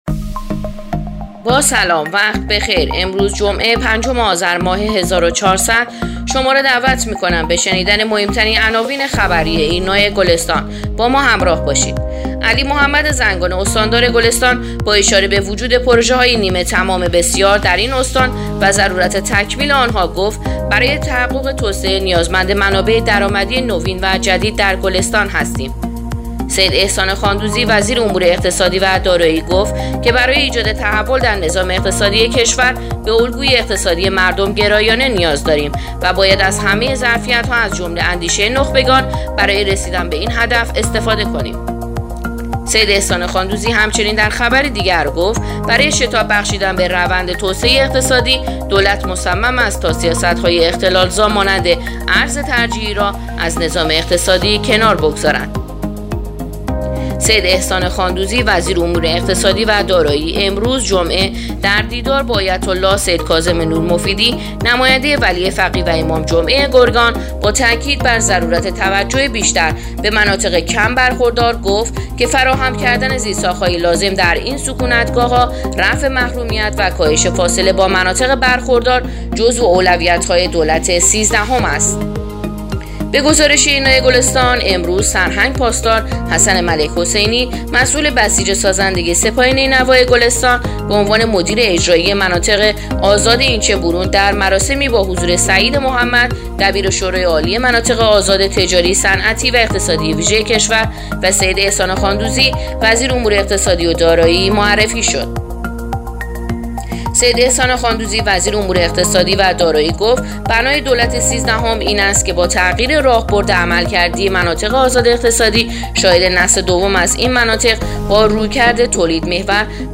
پادکست/اخبار شبانگاهی پنجم آذر ایرنا گلستان